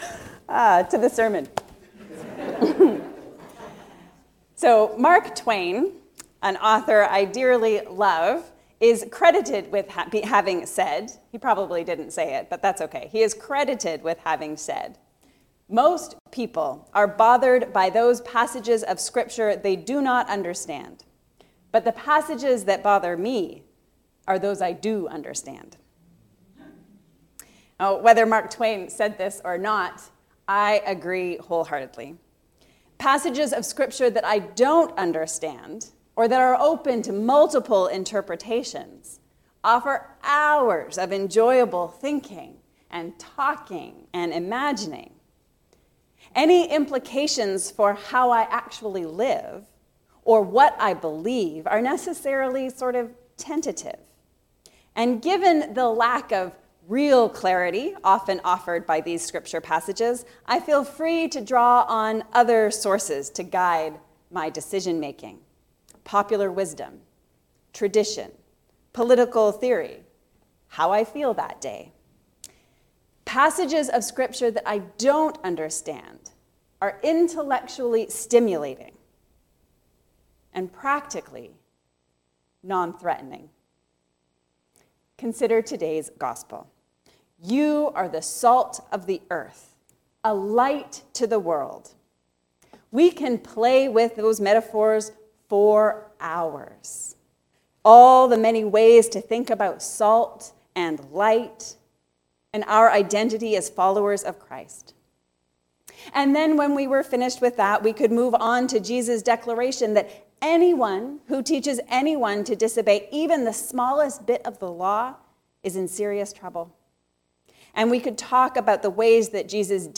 The fast the Lord chooses. A sermon on Isaiah 58:1-12